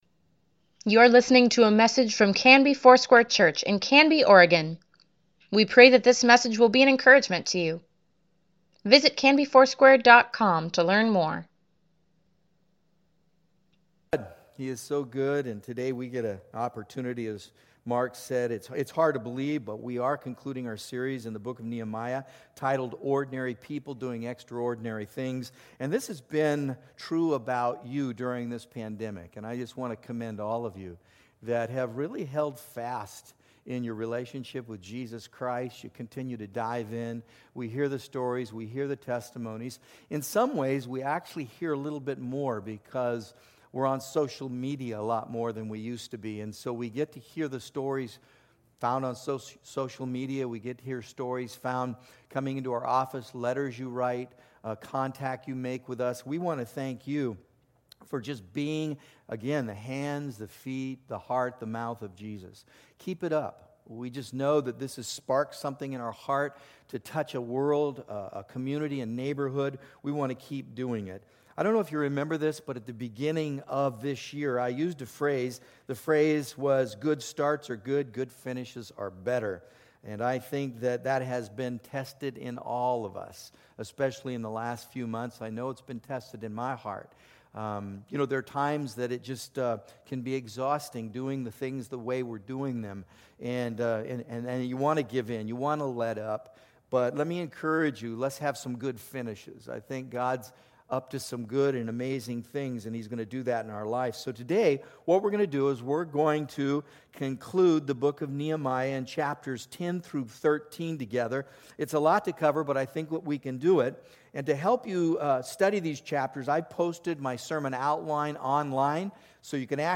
Weekly Email Water Baptism Prayer Events Sermons Give Care for Carus Nehemiah Conclulsion May 17, 2020 Good starts are good, but good finishes are better.